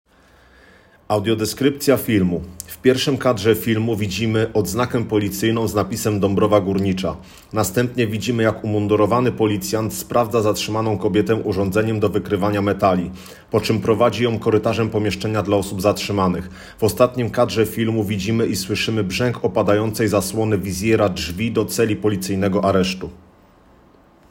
Nagranie audio Audiodeskrypcja_filmu-Zatrzymana_do_zabojstwa_.m4a